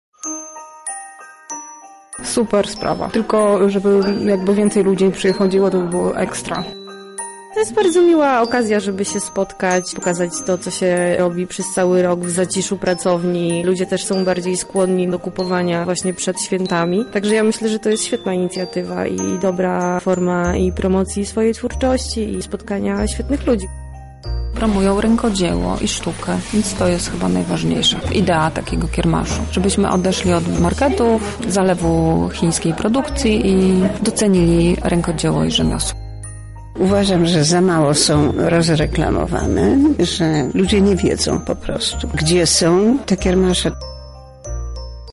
Uczestniczki opowiedziały naszej reporterce, co myślą o takiej inicjatywie.